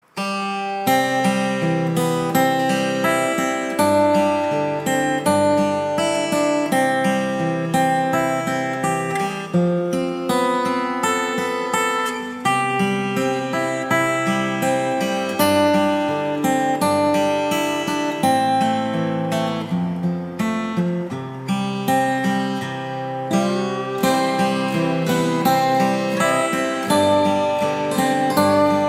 instrumental Ringtone